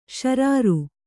♪ śarāru